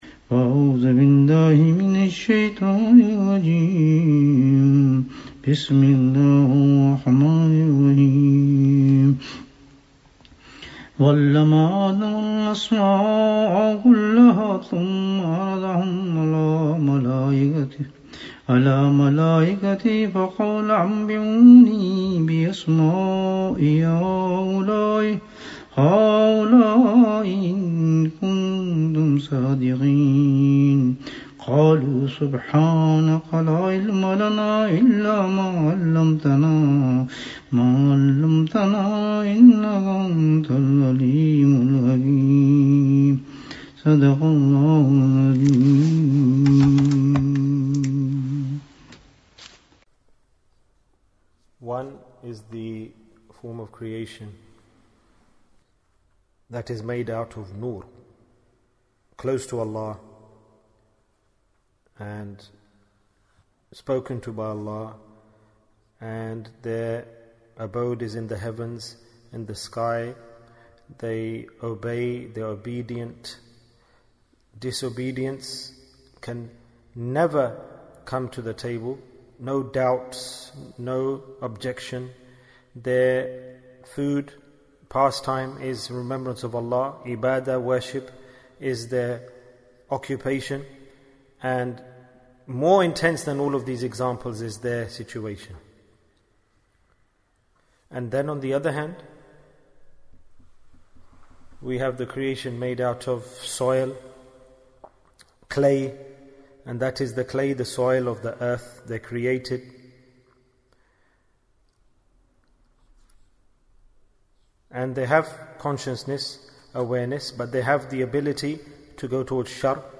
Which Knowledge Was Given to Adam (as)? - Dars 30 Bayan, 49 minutes12th July, 2020